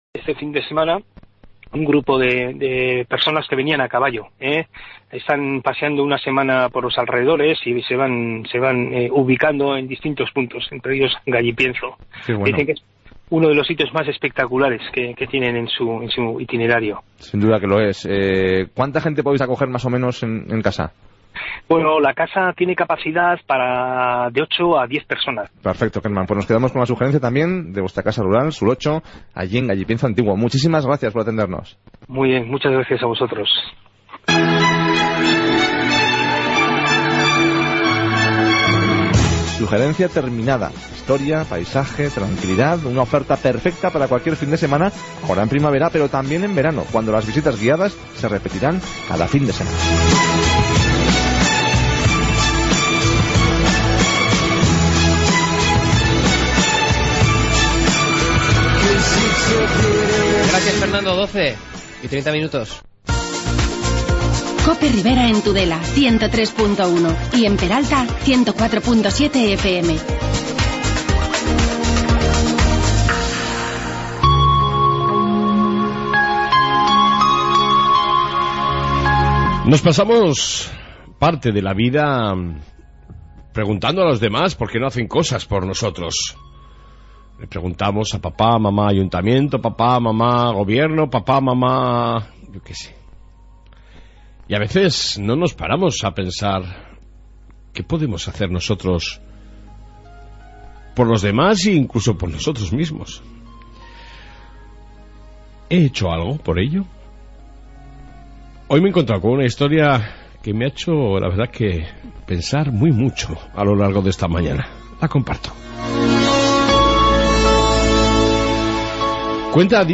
AUDIO: Reflexión, Información Policia Municipal, entrevista sobre las VI jornadas interculturales lasa y otras noticias...